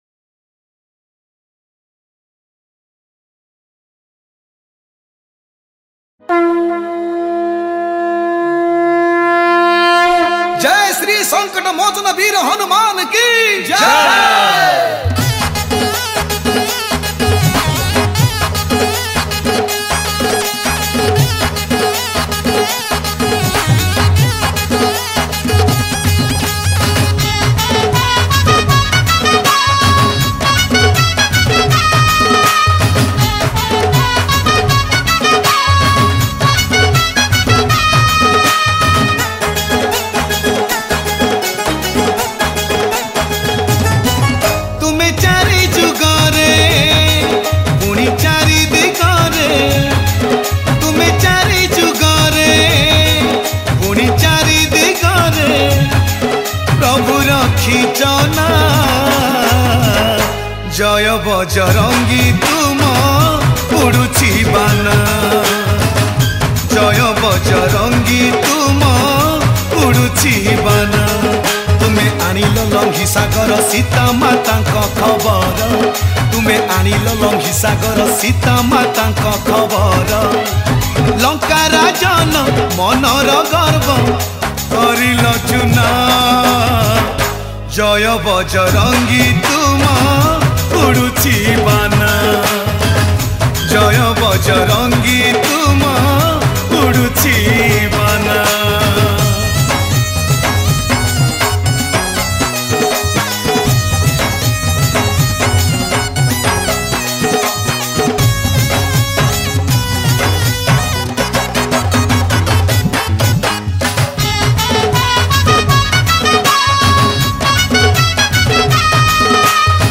Odia Hanuman Bhajan